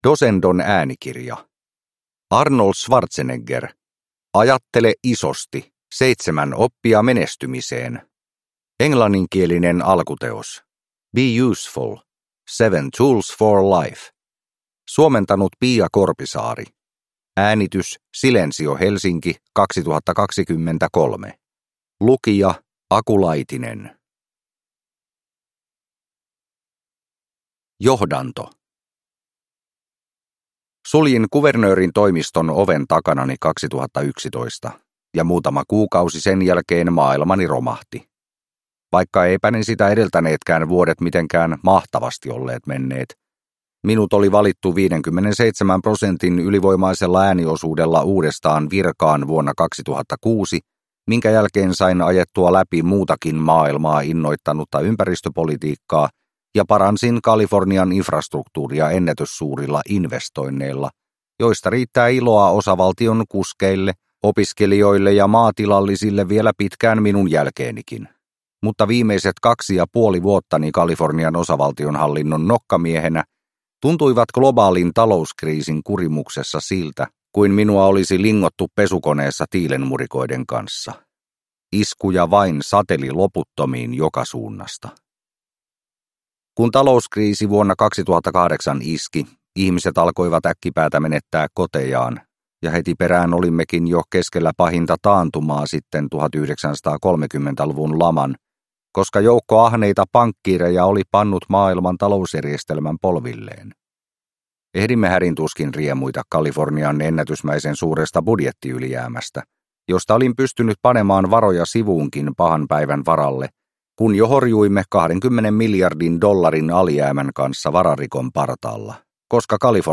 Ajattele isosti – Ljudbok – Laddas ner